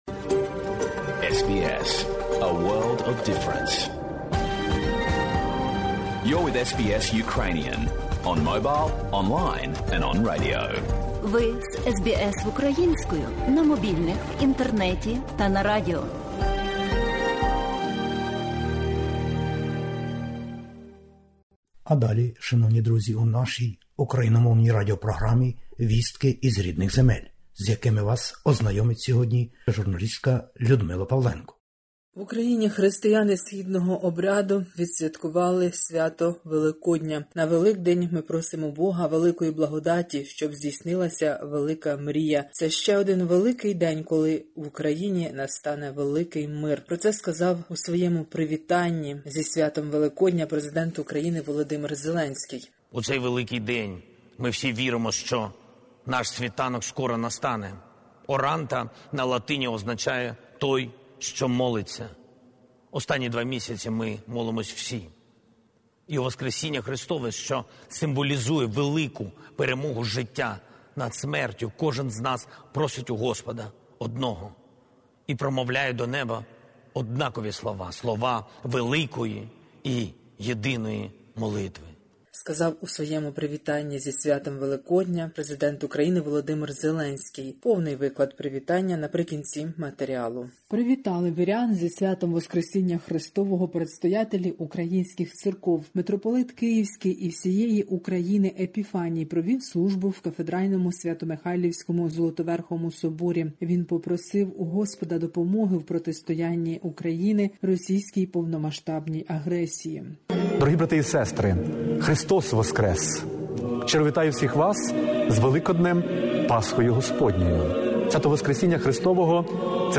Добірка новин із України. Великдень і війна - привітання-звернення Президента України.